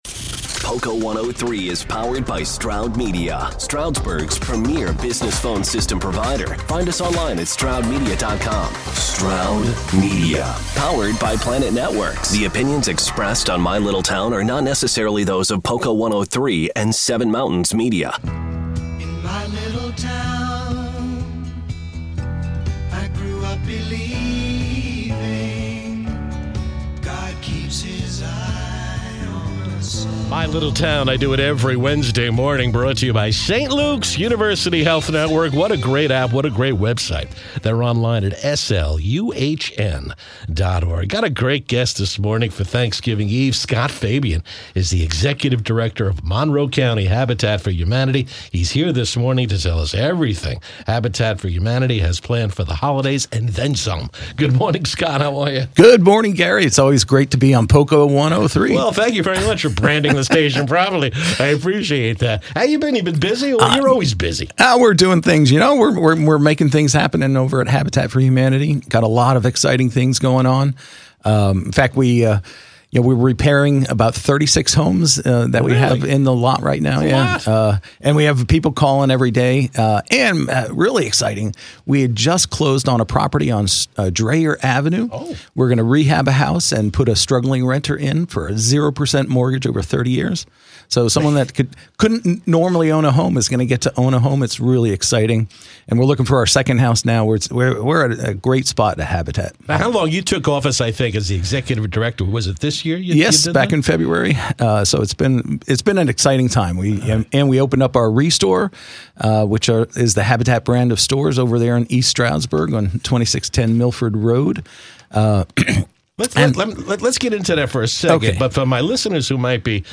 On the Radio: POCO 103 – ‘My Little Town’